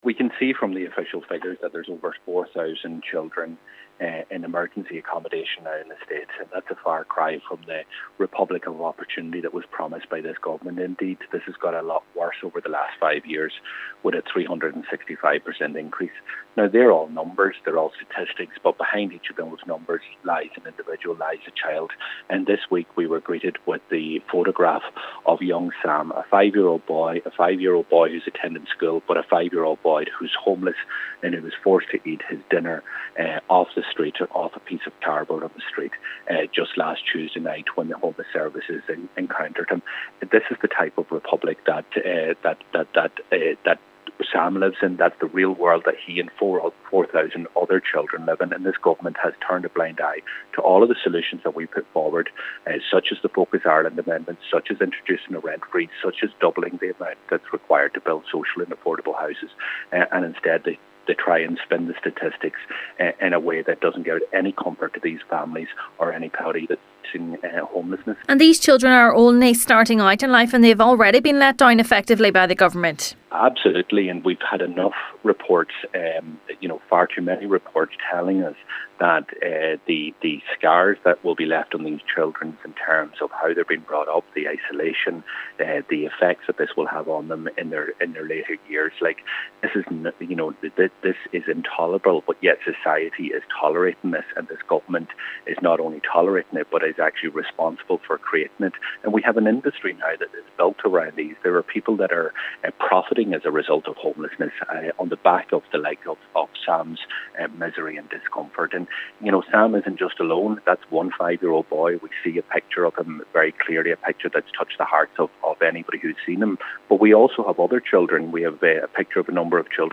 Donegal Deputy Pearse Doherty has told the Dail that the Government is failing to focus on the people behind the statistics.